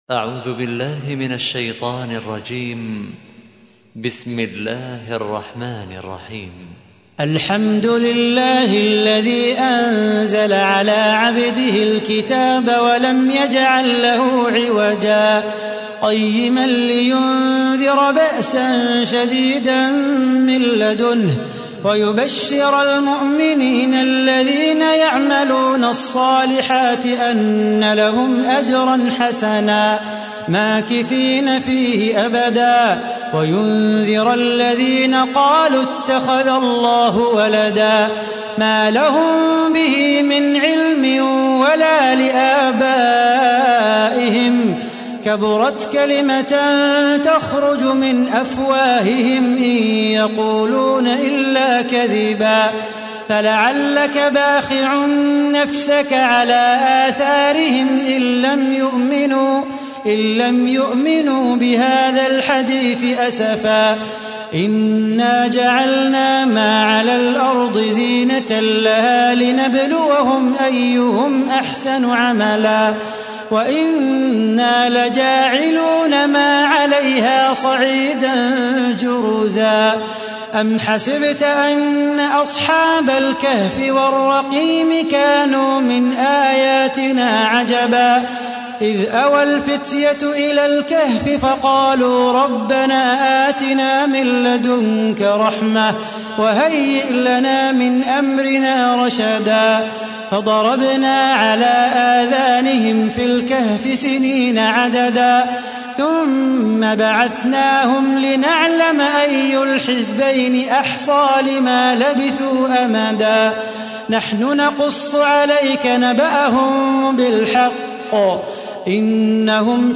تحميل : 18. سورة الكهف / القارئ صلاح بو خاطر / القرآن الكريم / موقع يا حسين